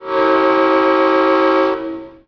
Here's my Old Cast P5A horn which was found on the first order of F40s and ended up on other F40s.
This is also one of my favorite types of horns they are getting rare to hear these days.
"P5A horn"
F40P5AOC1loopshort.wav